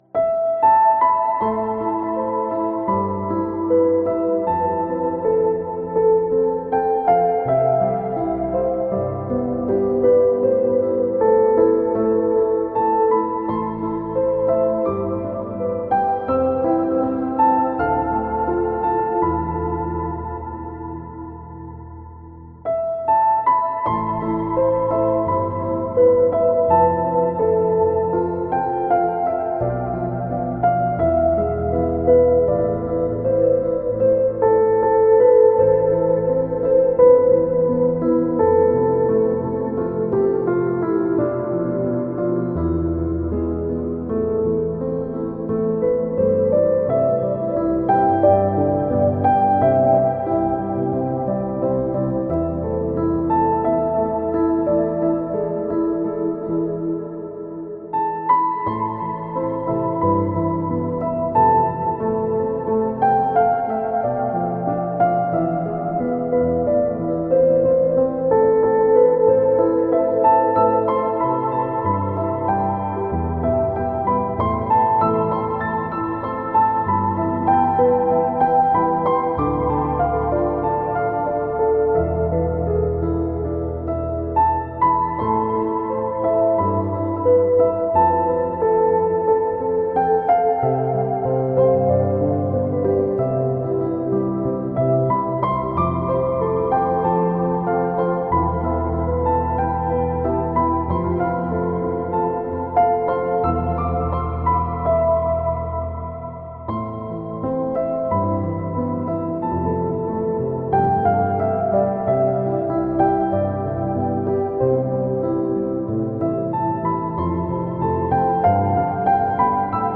Sad Piano